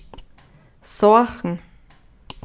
soachn / Begriff-ABC / Mundart / Tiroler AT / Home - Tiroler Versicherung